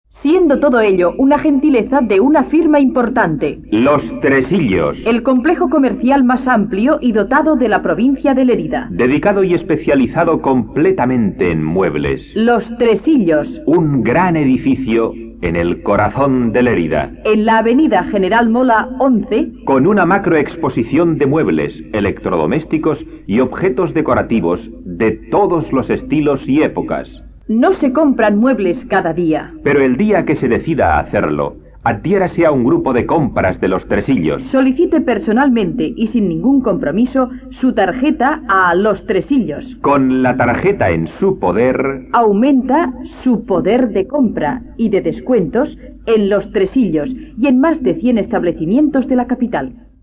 Publicitat de Los Tresillos